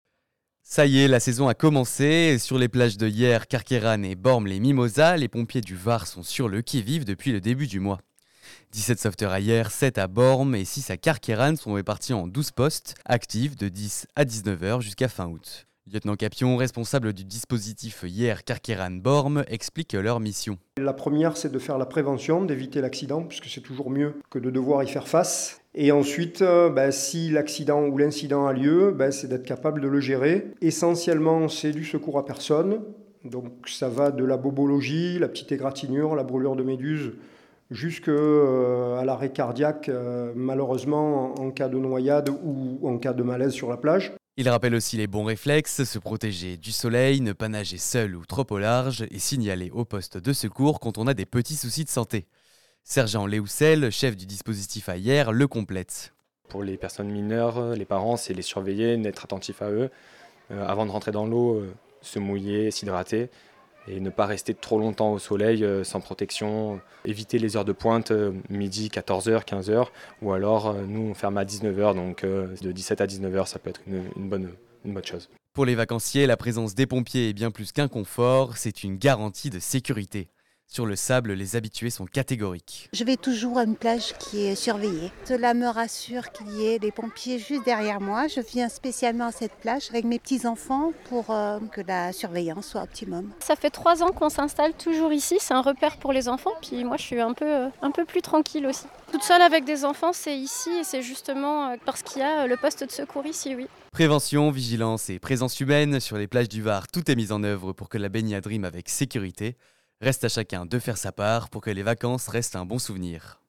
REPORTAGE SAPEURS-POMPIERS SAUVETEURS SUR LES PLAGES VAROISES